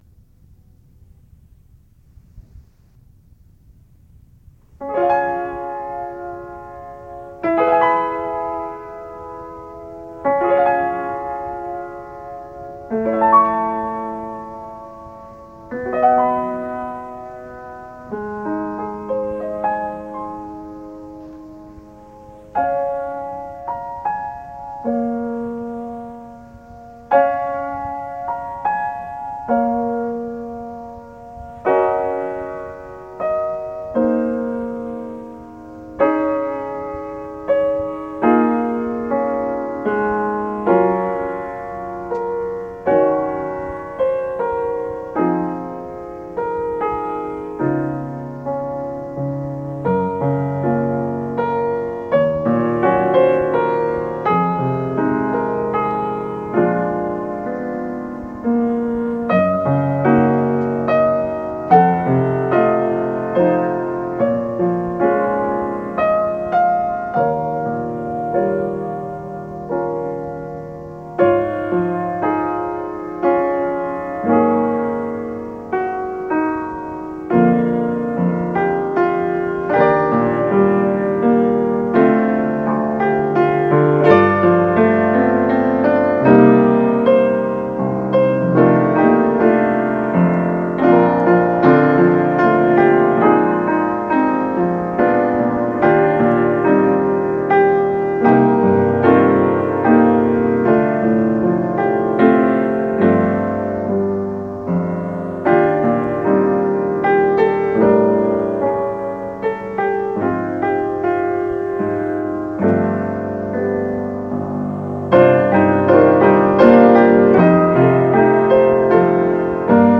Here are some of my favorite piano arrangements: O Come All Ye Faithful, Silent Night, While Shepherds Watched Their Flocks, Three Carols, Little Drummer Boy